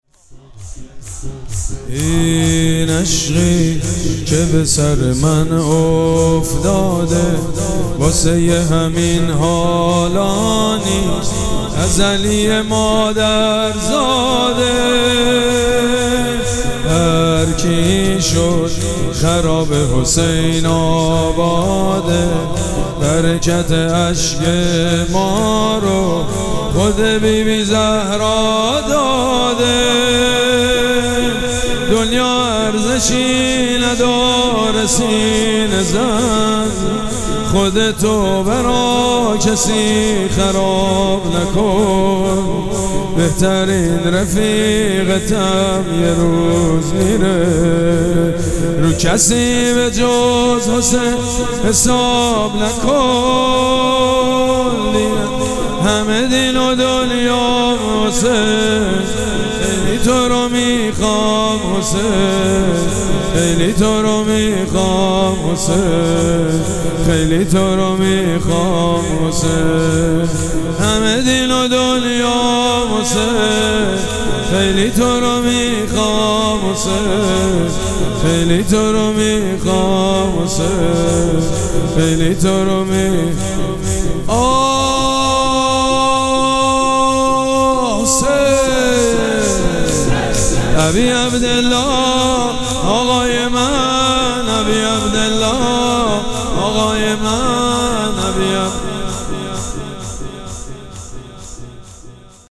شب چهارم مراسم عزاداری اربعین حسینی ۱۴۴۷
شور
مداح